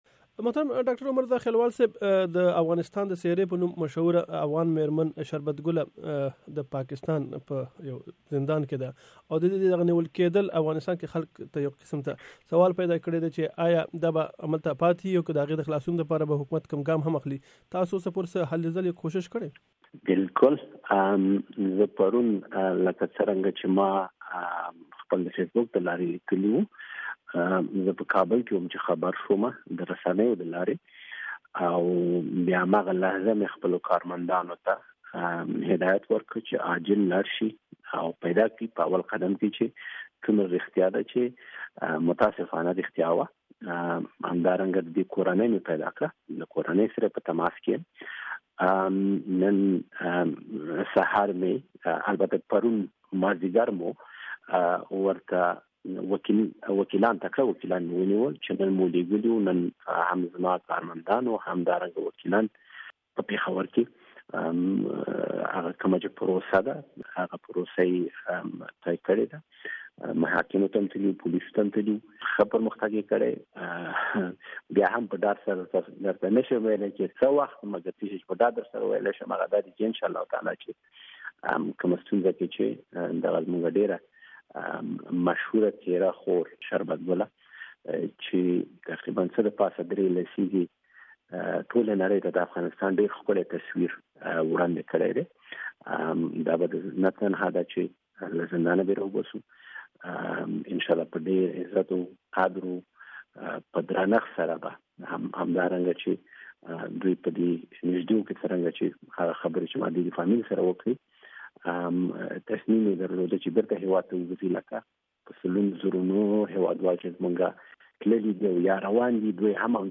ډاکټر زاخیلوال د امریکا غږ اشنا راډیو سره مرکه کې وویل چې د هغې د نیولو د ځای پته یی لگولې او لا دوخته یی پدې اړه زیار پیل کړی دی.
اسلام اباد کې د افغانستان سفیر ډاکټر عمر زاخیلوال سره مرکه